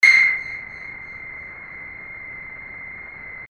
群众垫
描述：人群中的白噪声，没有什么特效
Tag: 140 bpm Techno Loops Soundscapes Loops 2.31 MB wav Key : Unknown FL Studio